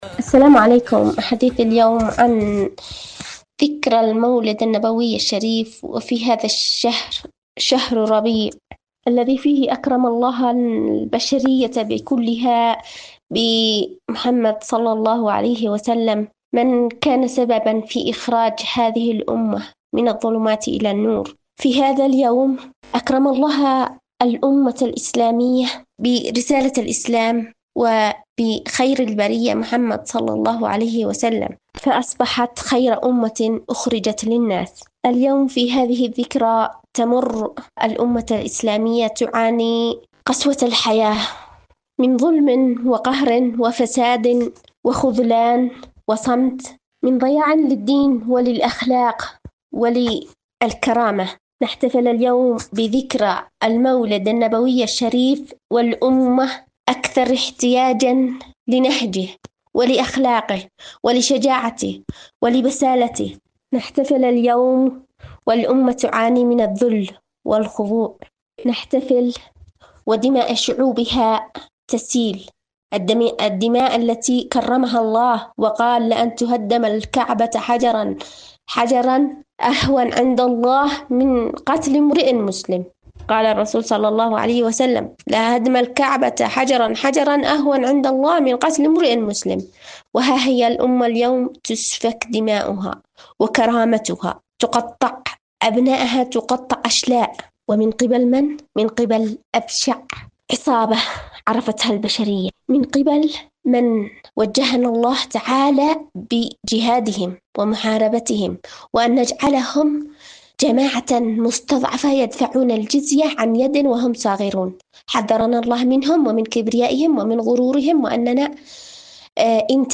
مقابلة
إذاعة طهران-مولد ربيع الهدى: مقابلة إذاعية